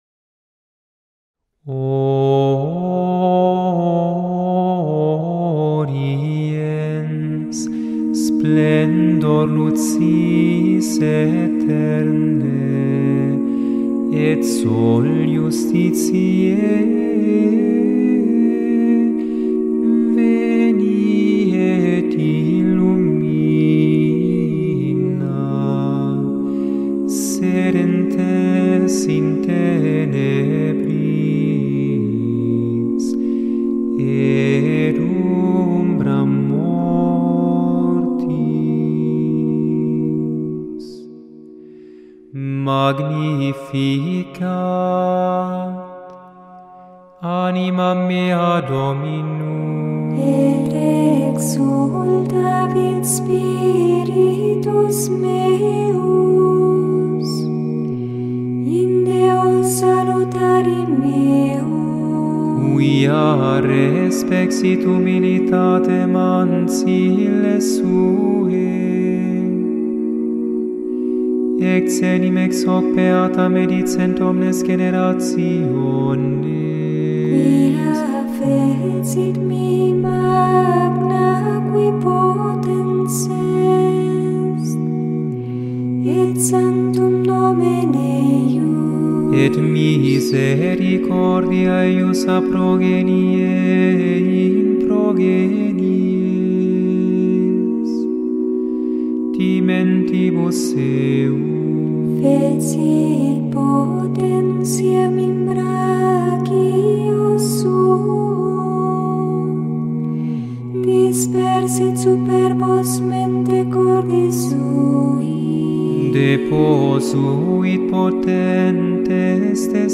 • Chaque jour, du 17 au 23 décembre (soir), on chante une antienne « Ô » dédiée à un Nom divin du Christ, tirée des prophéties de l’Ancien Testament.
Et voici des manifique enregistrement de Harpa Dei sur YouTube :
21dec-O-ORIENS-O-Antiphon-and-Magnificat.mp3